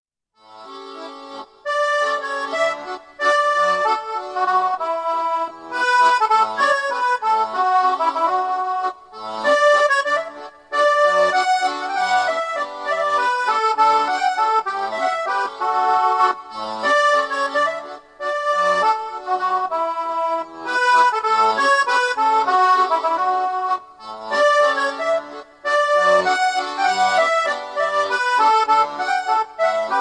folk melody just right for whistling